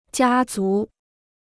家族 (jiā zú): family; clan.